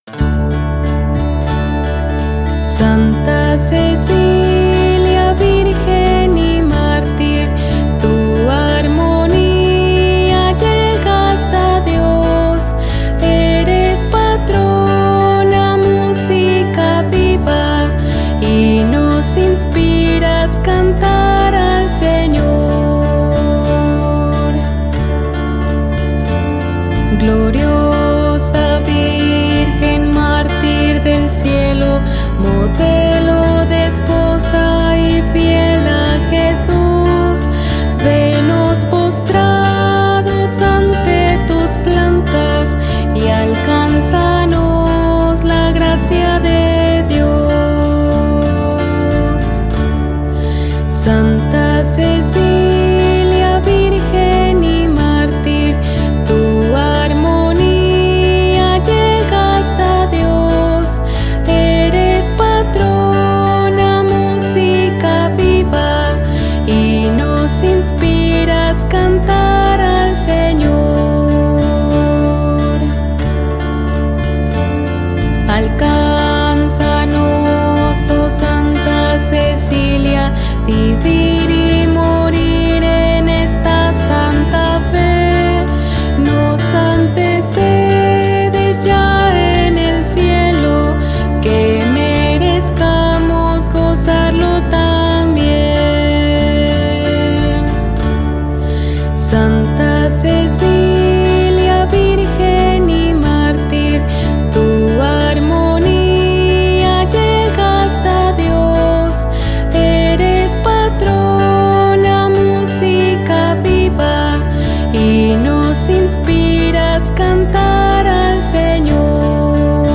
Himno-a-Santa-Cecilia.mp3